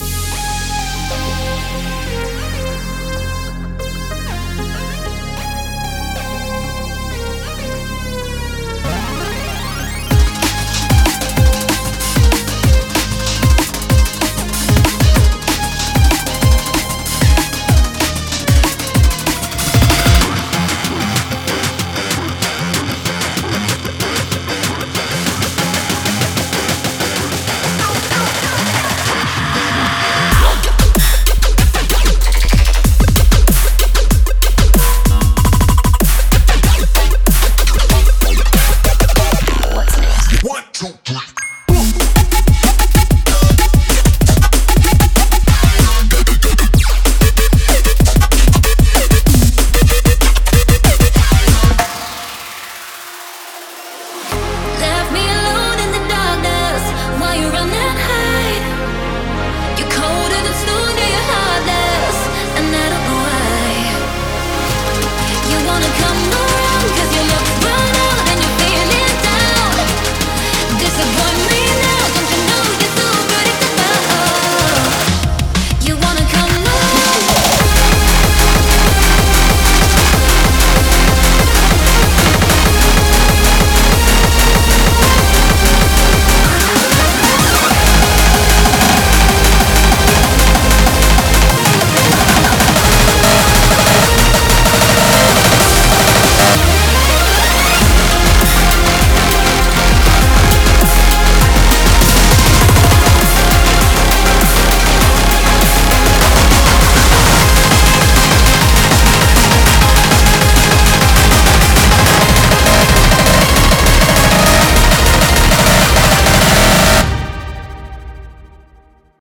BPM92-200
Audio QualityPerfect (High Quality)
Feel that BPM change!!